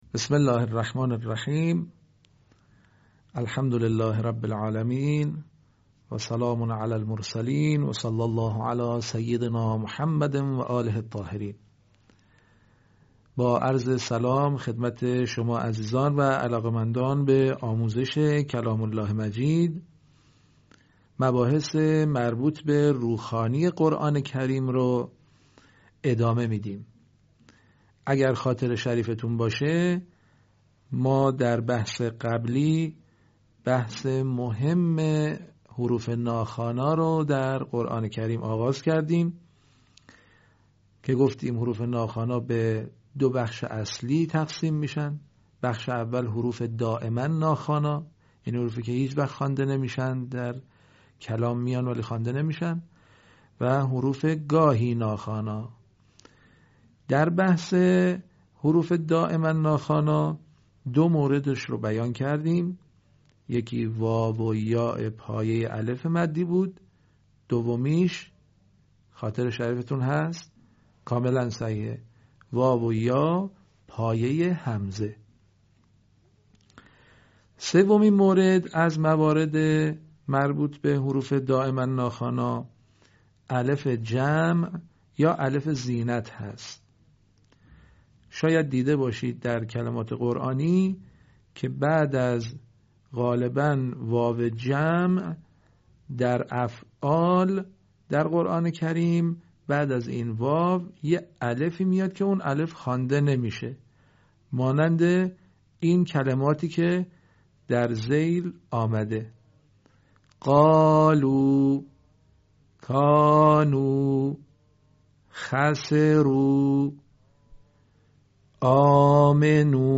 آموزش قرآن